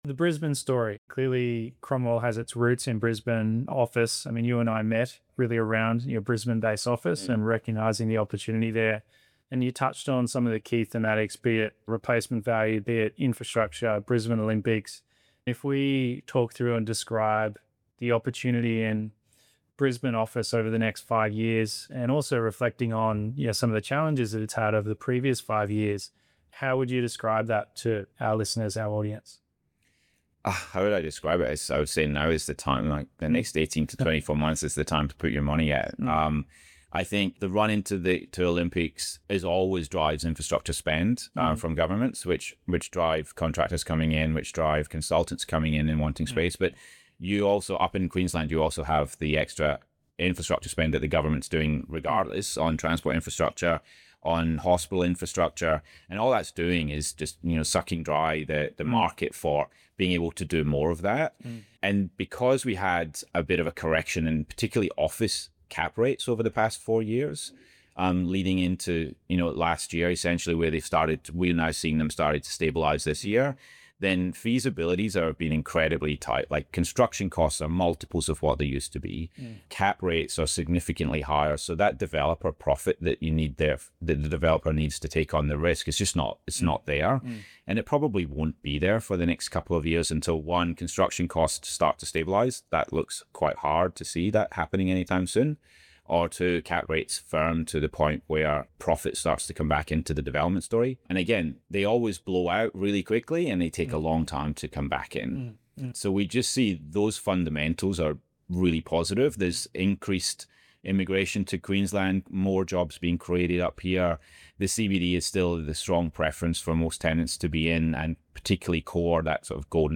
Podcast interview (7 mins)